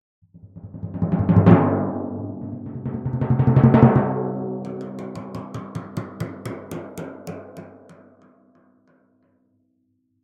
游戏和媒体DINGS " 06762夏威夷游戏完整版DING
描述：夏威夷游戏充满异国情调
标签： 开心 通知 游戏的LevelUp 夏威夷 成功 完整
声道立体声